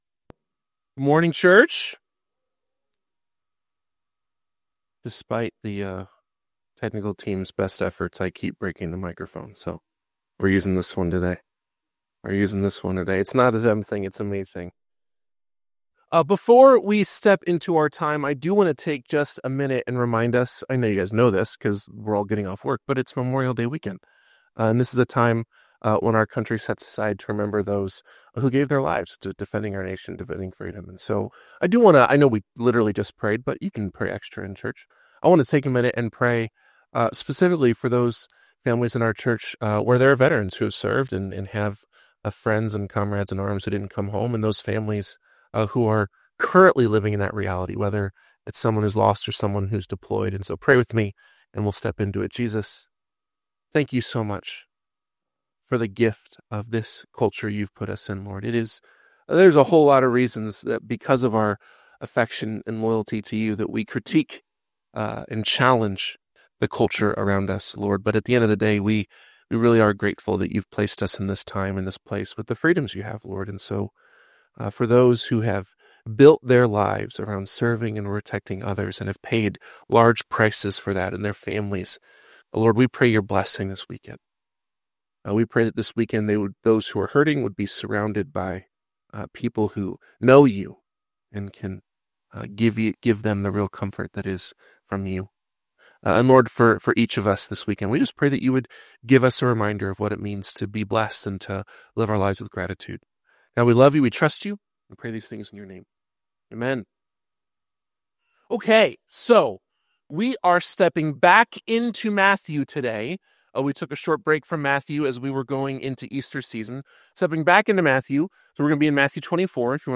This sermon challenges us to remain steadfast in our faith amidst the chaos of a broken world, reminding us that while the world may continue to burn, our hope is anchored in Christ's ultimate victory.